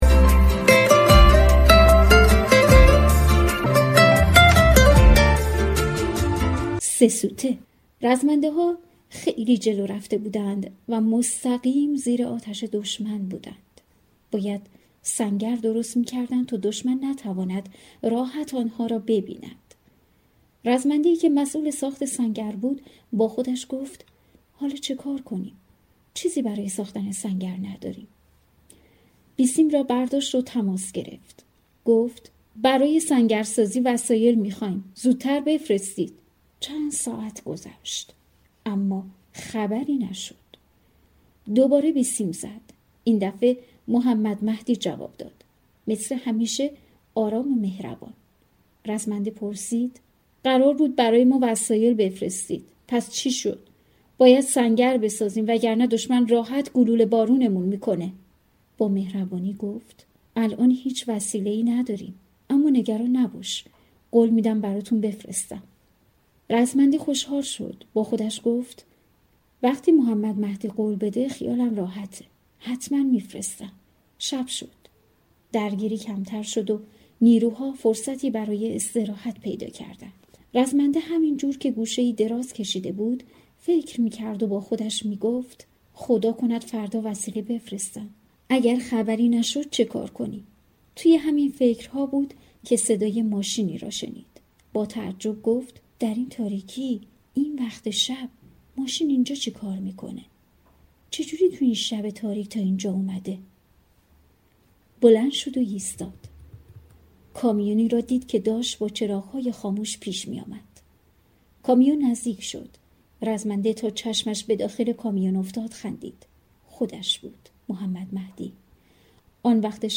قصه های قهرمان ها